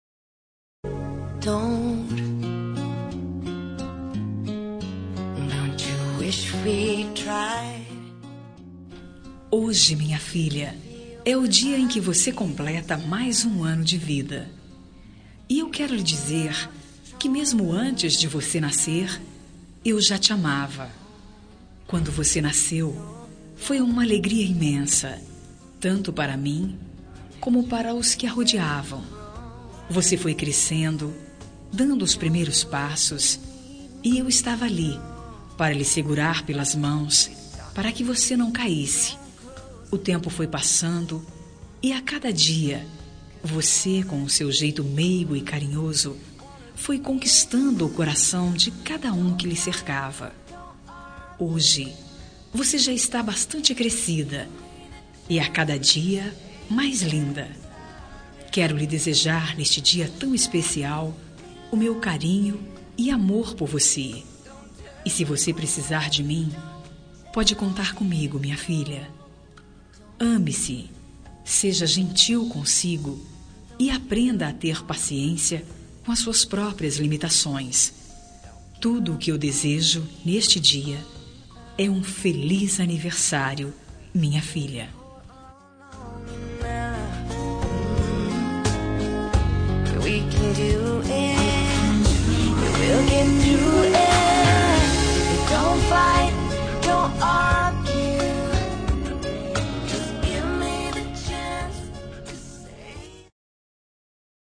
Telemensagem de Aniversário de Filha – Voz Feminina – Cód: 1764 – Linda